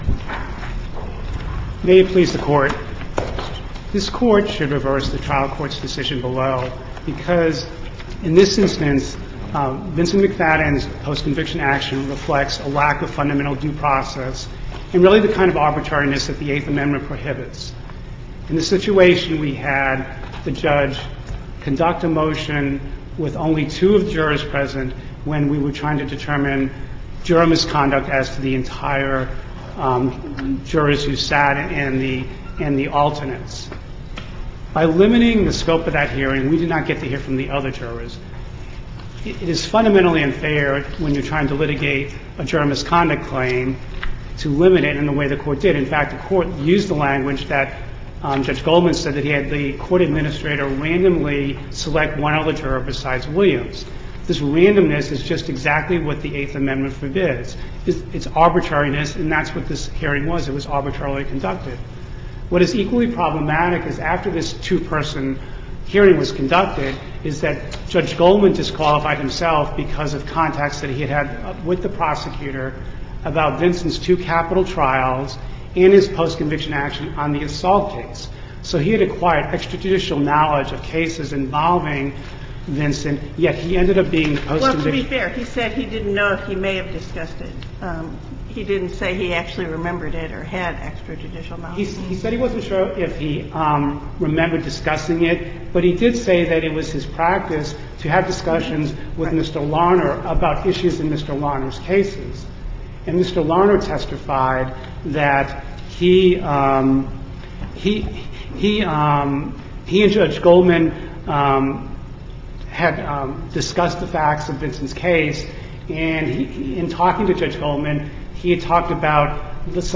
MP3 audio file of arguments in SC96718